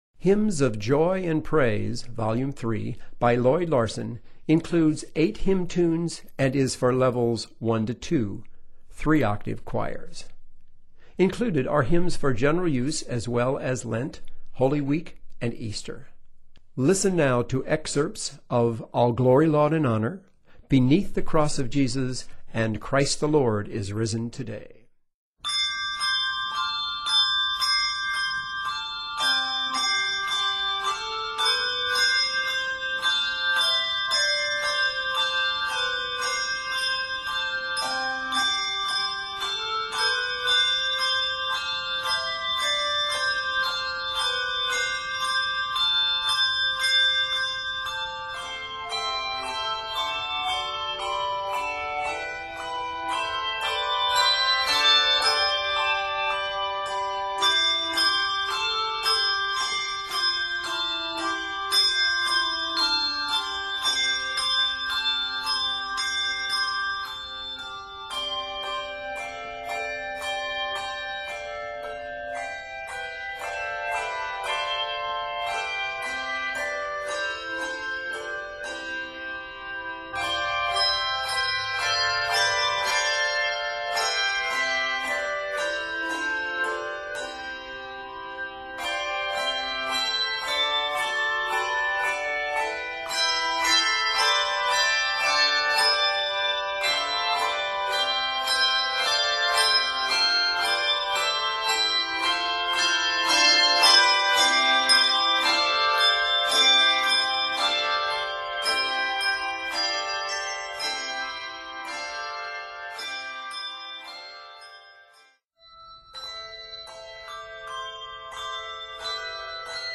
Octaves: 3